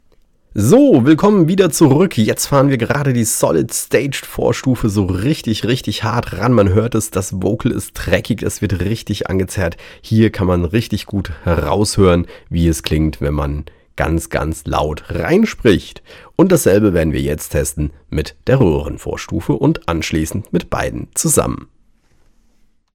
Wenn der Regler für Gain weiter aufgezogen wird, kommt der Klang etwas präsenter herüber und kann sehr aggressiv werden.
In den Klangbeispielen findest Du zahlreiche Aufnahmen mit unterschiedlichen Einstellungen für Gain und Mischungsverhältnis der beiden Schaltkreise.